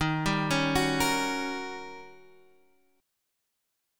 D# 7th Sharp 9th